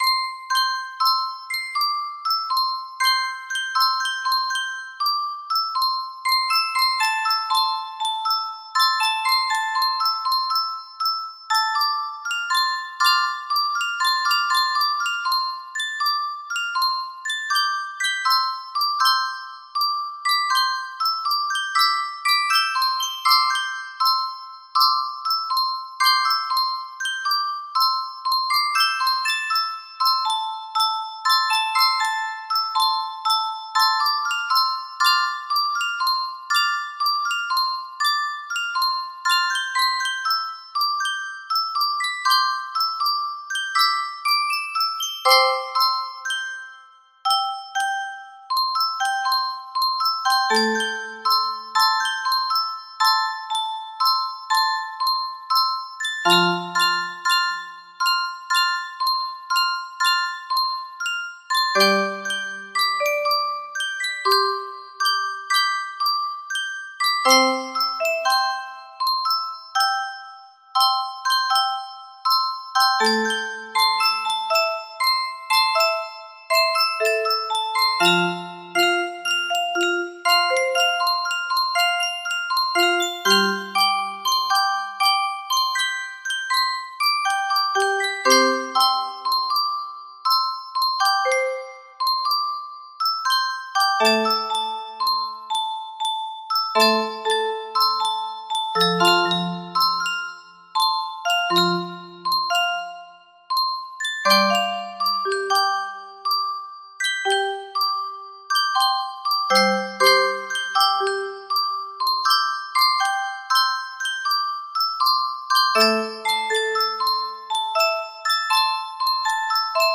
Full range 60
Done, Proper tempo, No Reds.